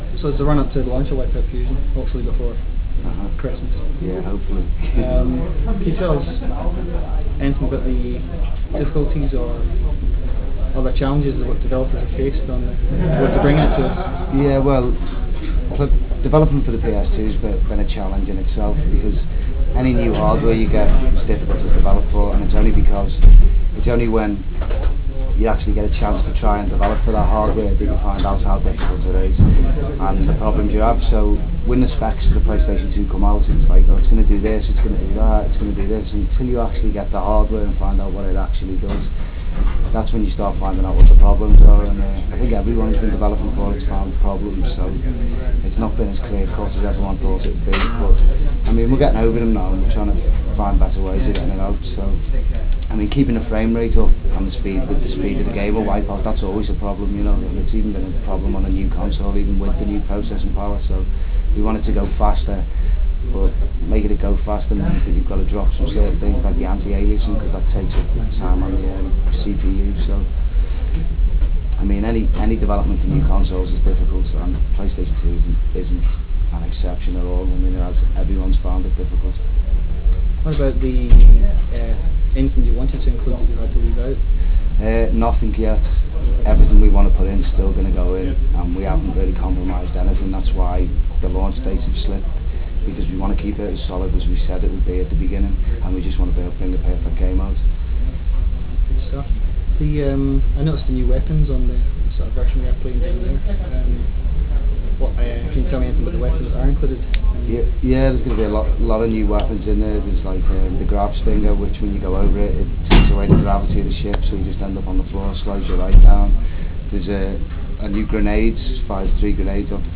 E3 Interview
With the dull roar from the crowded show floor of the Los Angeles convention center making even conversation with someone close difficult, we had to find a meeting room in order for my relatively basic recording equipment to pick us up from the background noise. This was no mean feat - there were corporate deals going on all over the shop and when we eventually did get a meeting cubicle it might as well have been in the middle of a busy junction for all the sound proofing it offered.